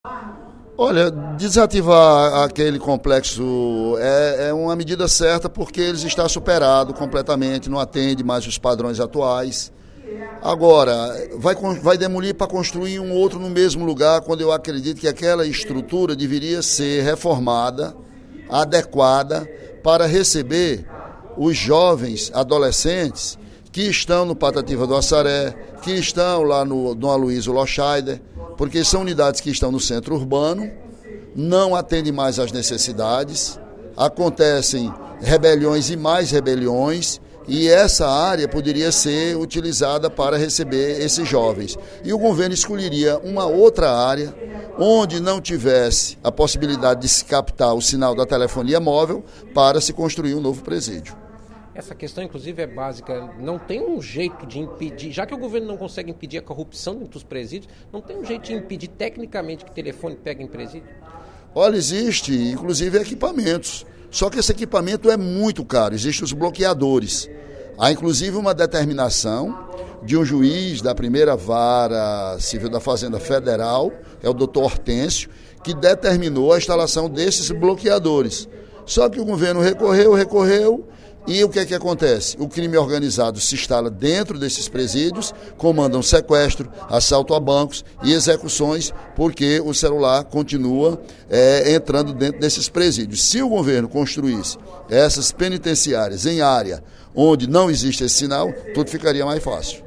No primeiro expediente da sessão plenária desta sexta-feira (16/08), o deputado Ely Aguiar (PSDC) sugeriu ao Governo do Estado a reestruturação do Instituto Penal Paulo Sarasate (IPPS) para atender e ressocializar adolescentes infratores.